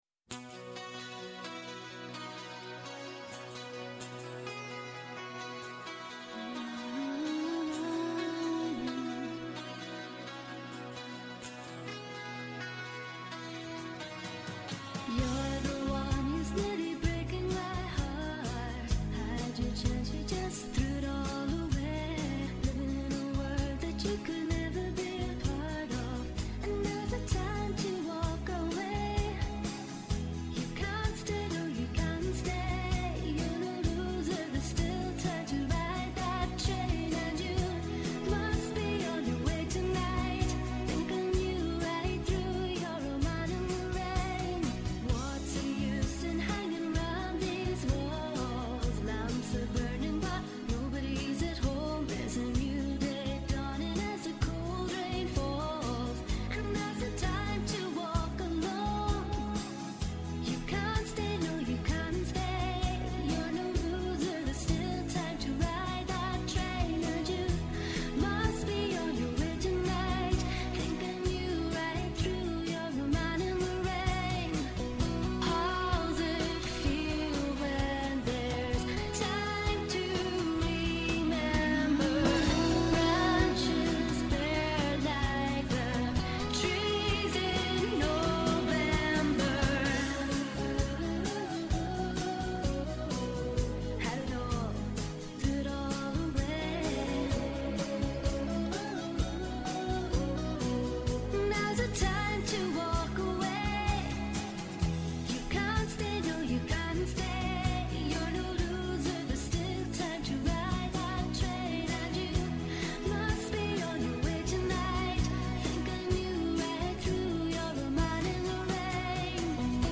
Απόψε καλεσμένοι οι πρωταγωνιστές της παράστασης «Ακρότητες»